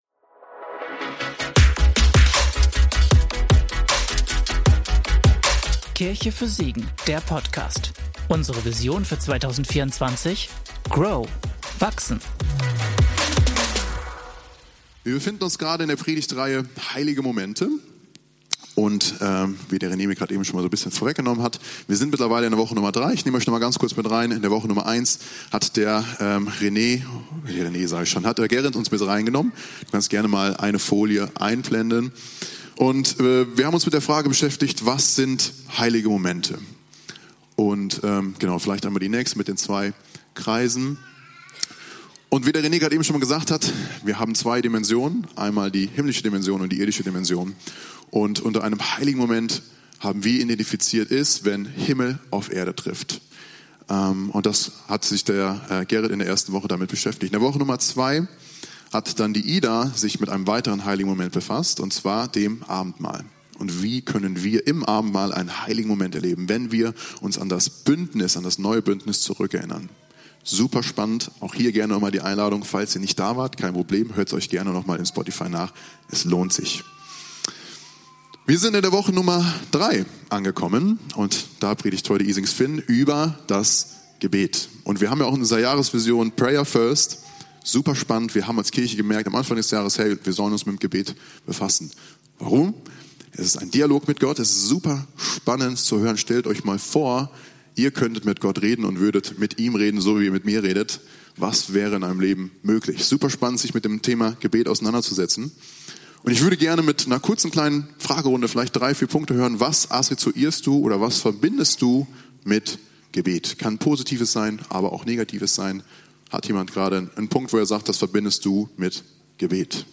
Gebet - Predigtpodcast